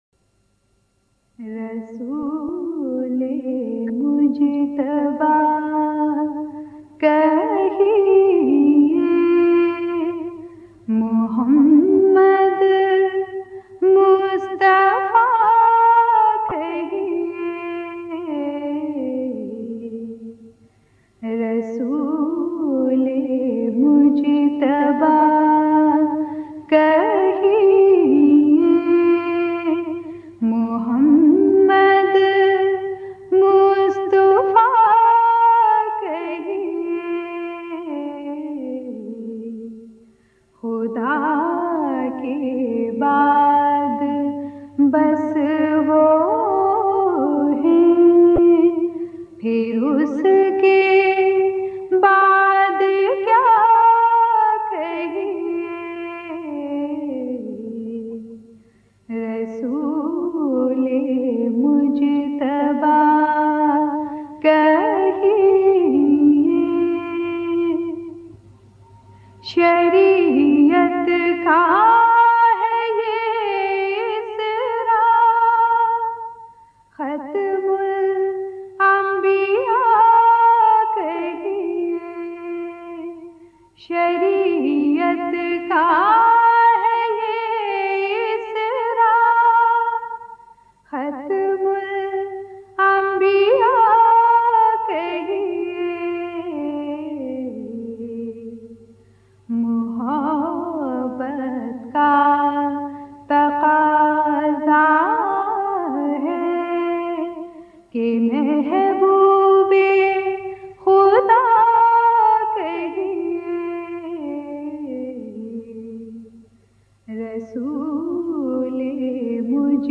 Naats
Khatoon Naat Khwan